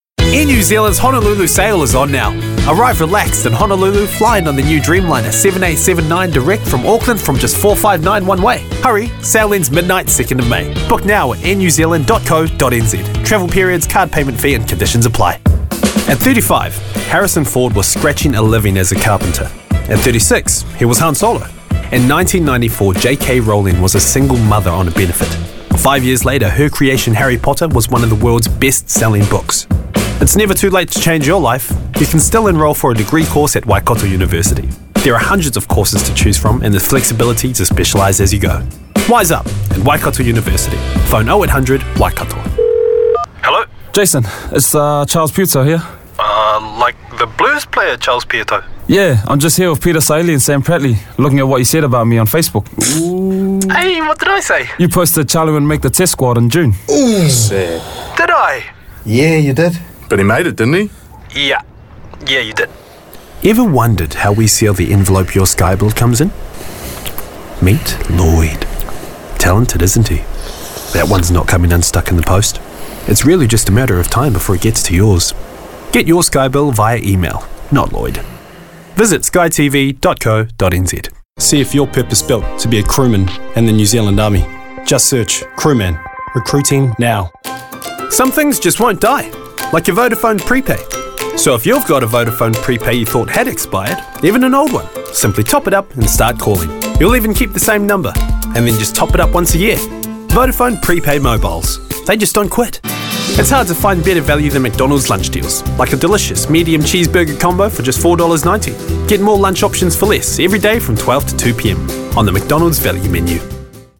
Adult, Young Adult
Accents: English | New Zealand pasifika standard us te reo maori
commercial
warm